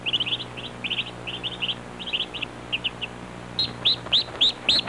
Baby Birds Sound Effect
Download a high-quality baby birds sound effect.
baby-birds.mp3